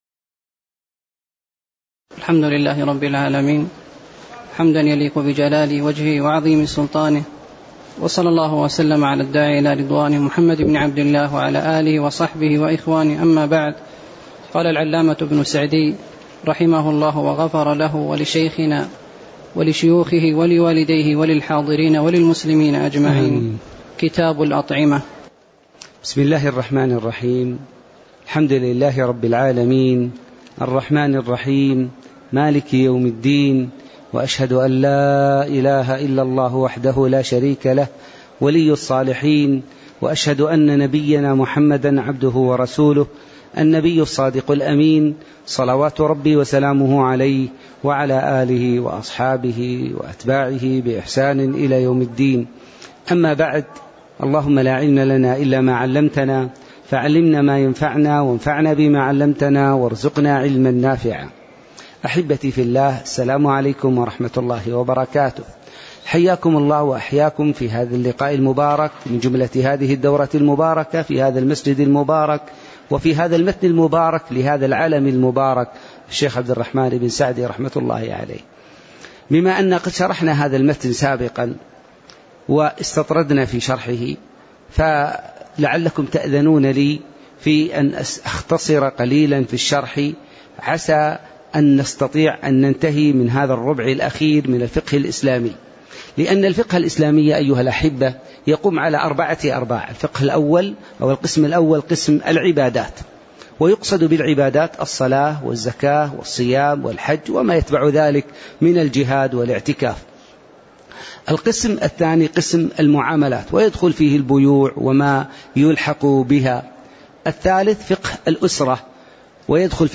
تاريخ النشر ٢١ شوال ١٤٣٧ هـ المكان: المسجد النبوي الشيخ